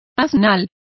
Also find out how asnal is pronounced correctly.